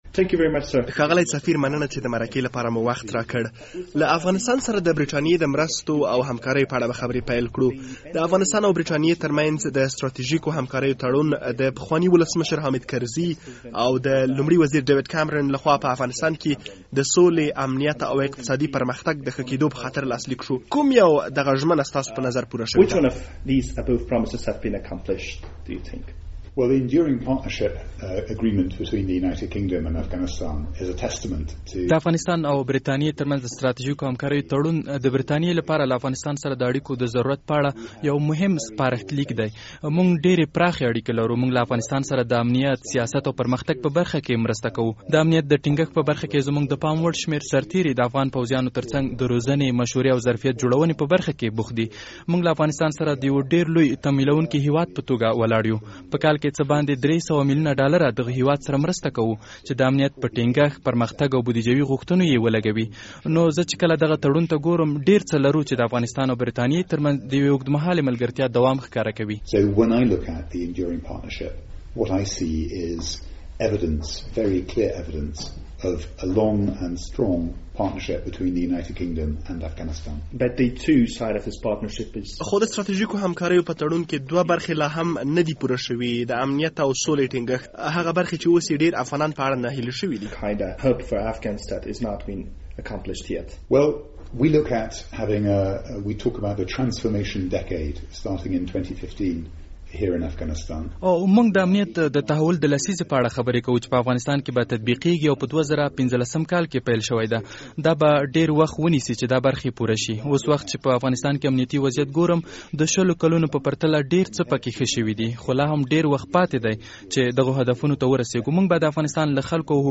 مرکه
ډومینیک جرمي له ازادي راډيو سره په ځانګړې مرکه کې وویل چې هېواد به یې د لوی تمویلونکې په توګه د افغانستان تر څنګ پاتې کېږي څو دغه هېواد وکولای شي په بیلابیلو برخو کې سوکالۍ ته ورسیږي.